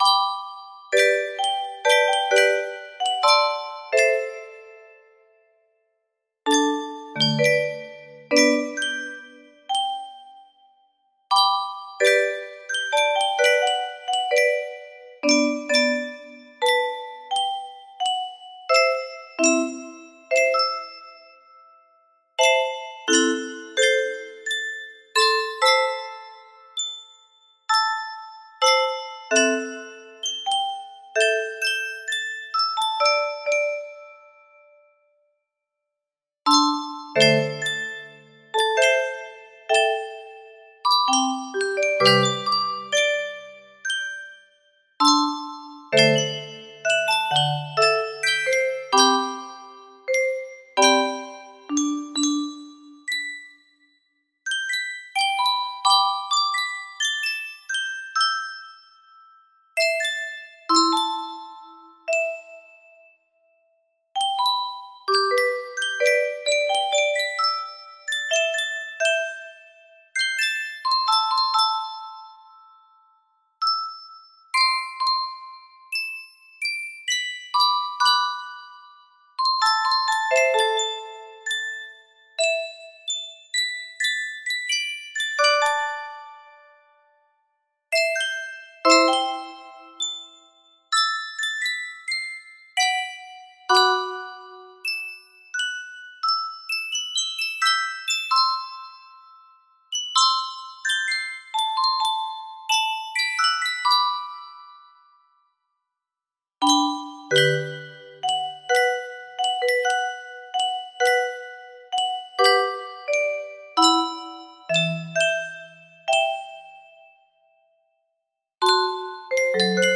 Full range 60
(Original Composition)